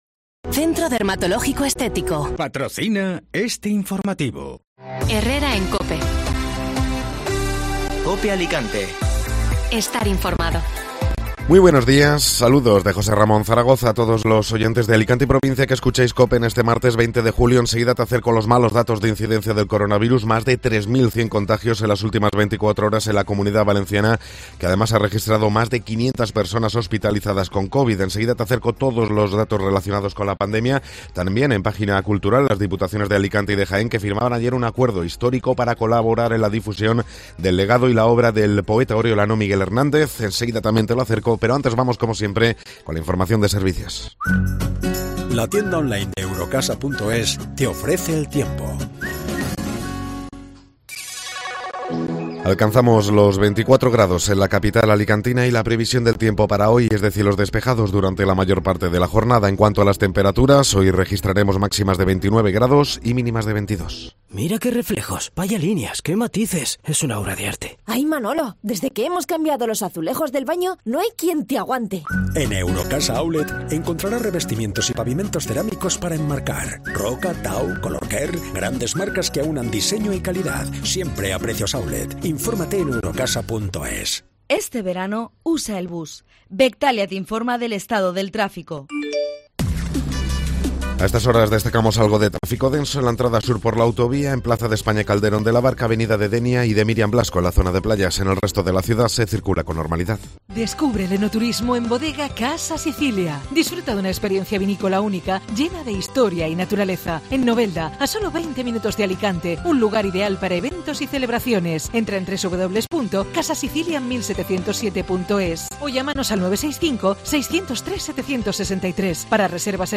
Informativo Matinal (Martes 20 de Julio)